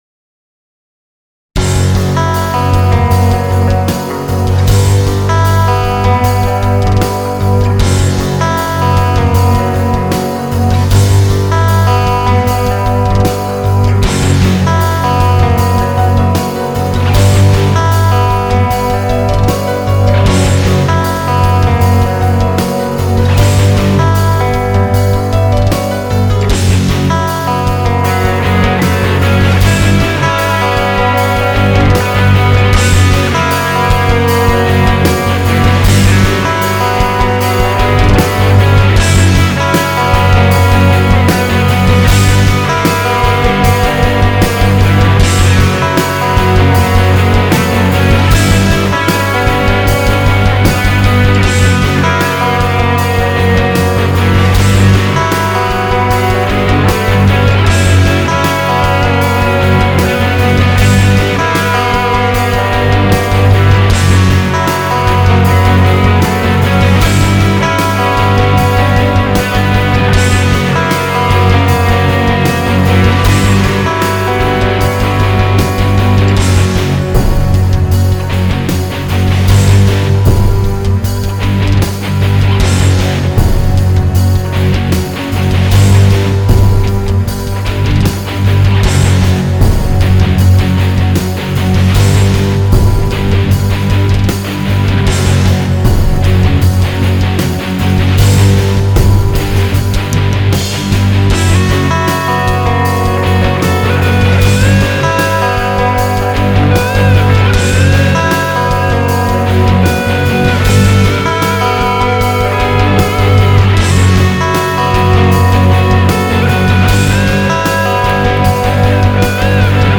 great ambient track!!